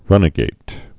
(rŭnə-gāt)